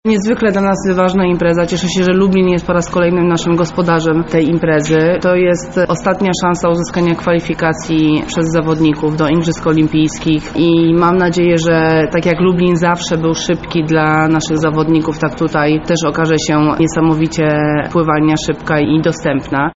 W czwartek na pływalni Aqua Lublin odbyła się specjalna konferencja prasowa, wraz z którą rozpoczęliśmy odliczanie do startu mistrzostw.
Otylia Jędrzejczak – zapowiada Otylia Jędrzejczak, prezes Polskiego Związku Pływackiego.